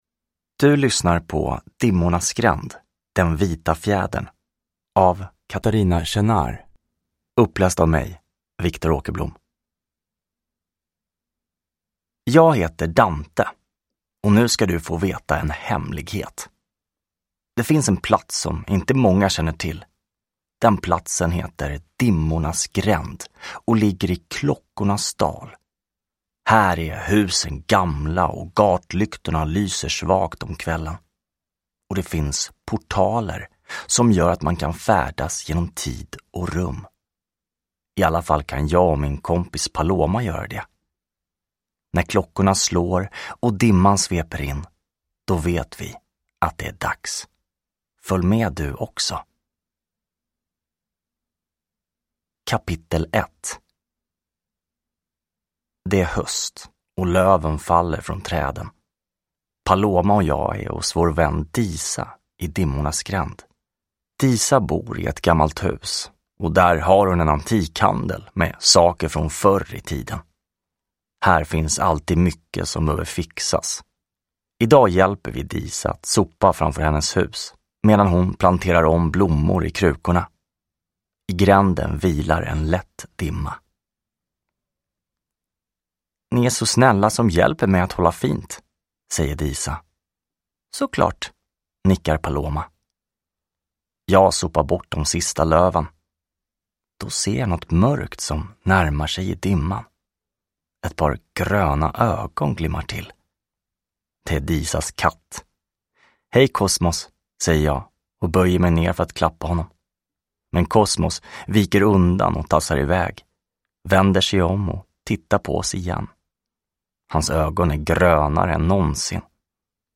Den vita fjädern – Ljudbok